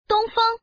Index of /client/common_mahjong_tianjin/mahjonghntj/update/1307/res/sfx/woman/